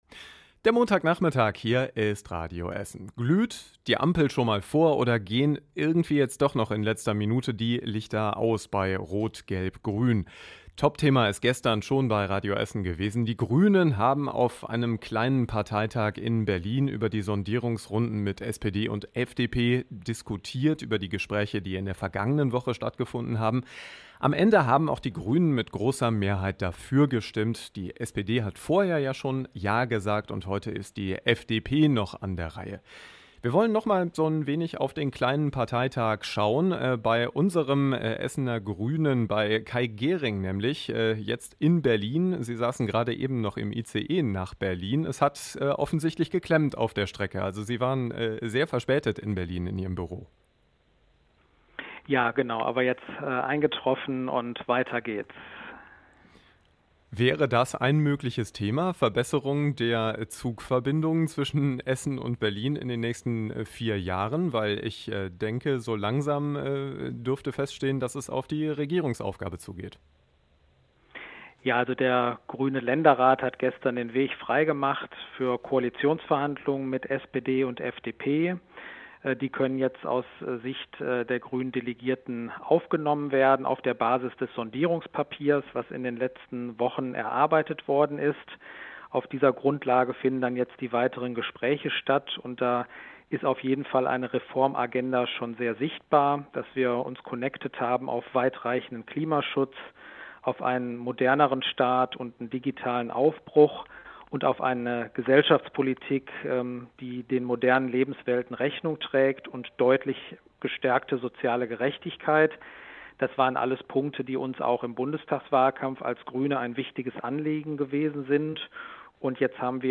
Wir haben unter anderem mit Kai Gehring von den Grünen gesprochen.